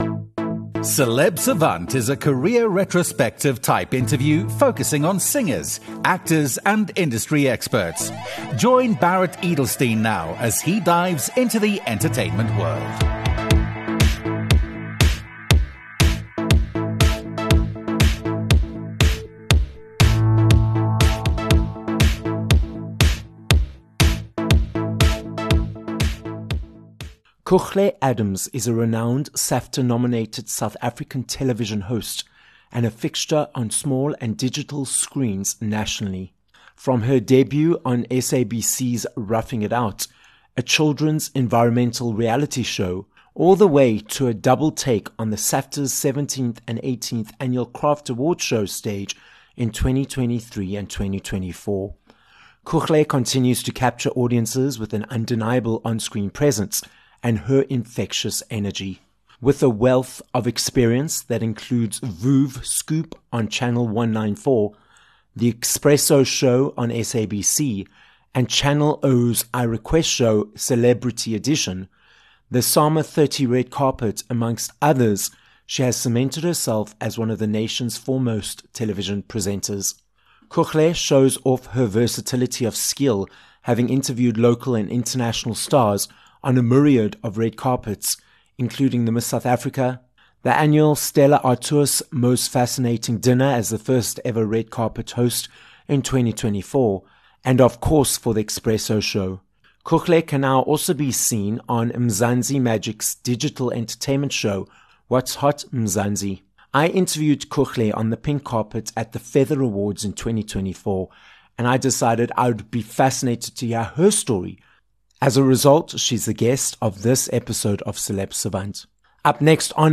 Each week we will have long-form career retrospective type interviews with celebrities namely, singers, actors and industry experts.